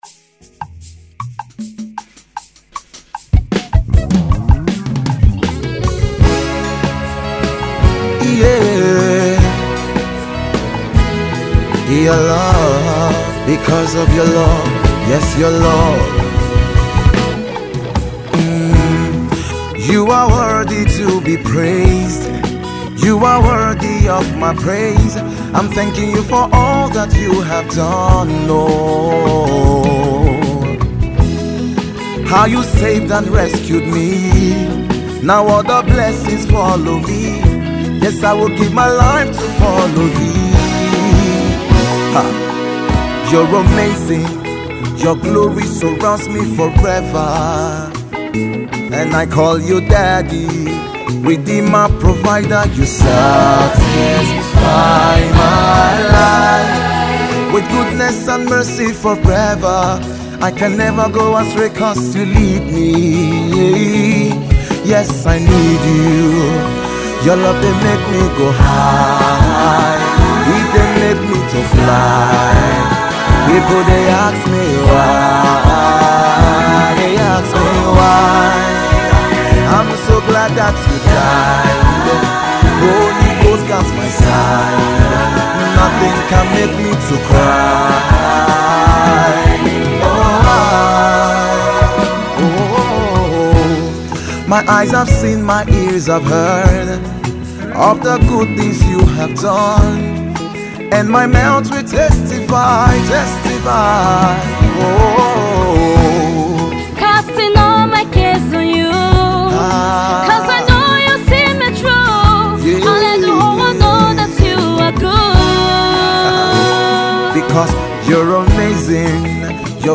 Gospel Music
spirit filled track